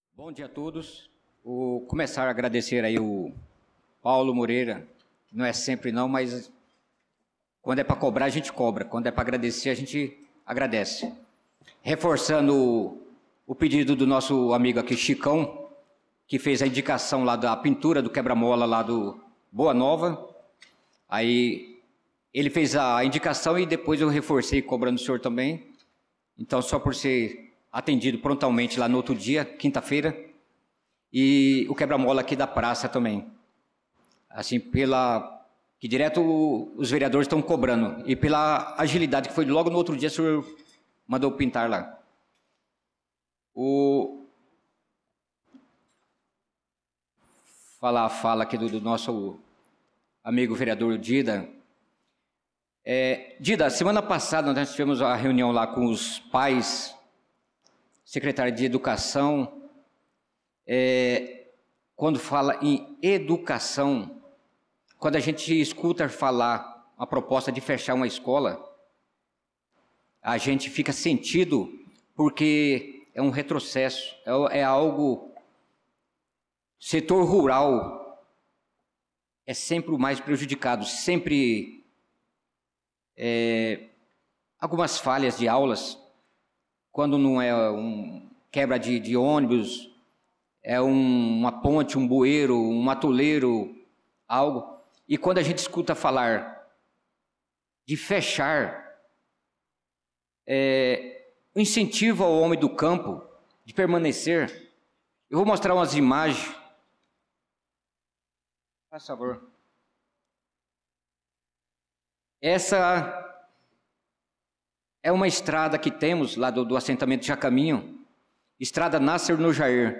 Pronunciamento do vereador Naldo da Pista na Sessão Ordinária do dia 07/07/2025.